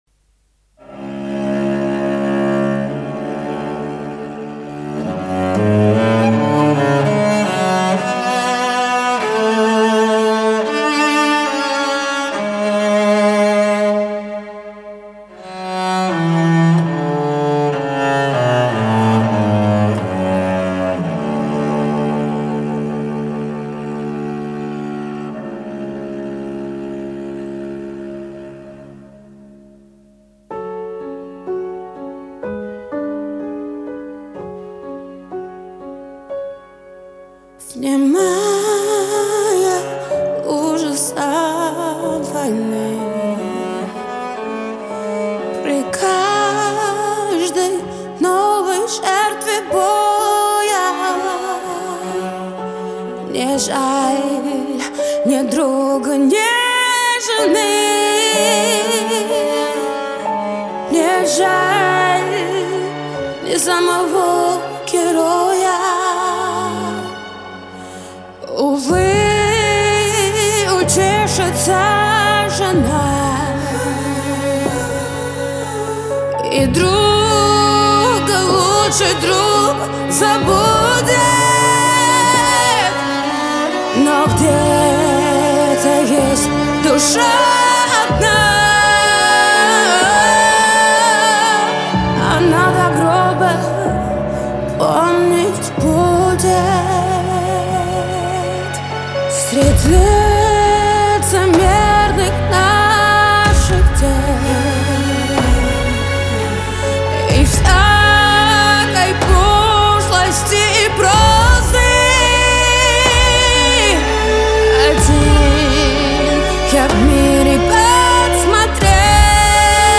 Русская музыка